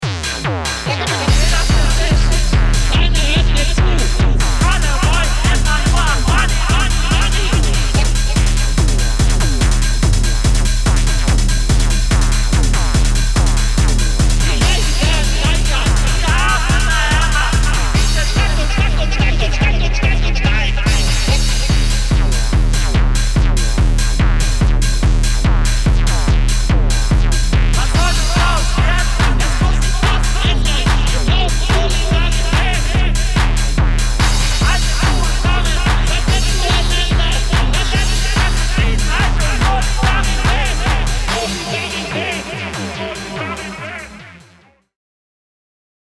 Electro Techno Acid